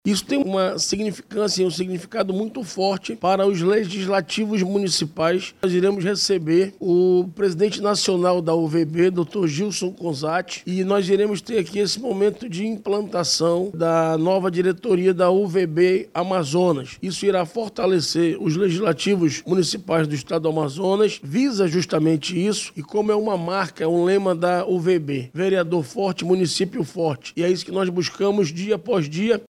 O presidente da Casa Legislativa, o vereador Davi Reis, do Avante, explica que além de contar com a presença do presidente nacional da entidade, o encontro vai dar posse à nova diretoria da União dos Vereadores do Brasil – UVB, no Amazonas.